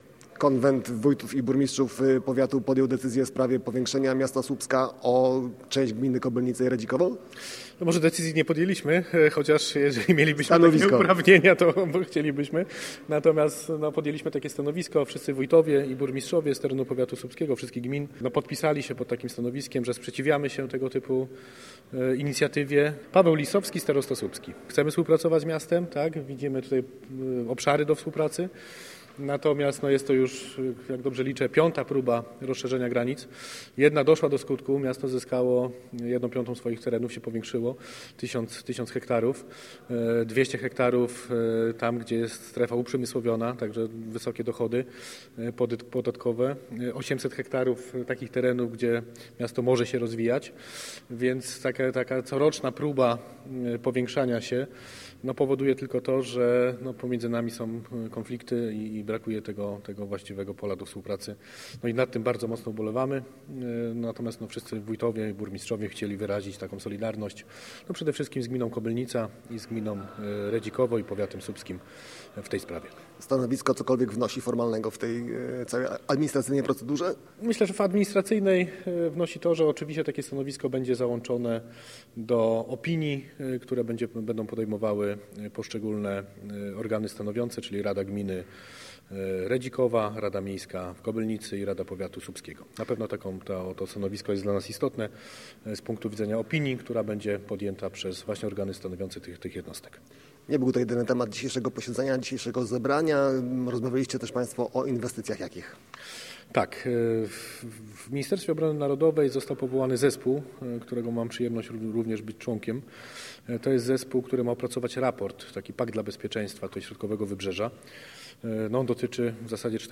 – Pod wspólnym stanowiskiem podpisali się wszyscy samorządowcy – informuje starosta słupski Paweł Lisowski.
starosta_8_15.mp3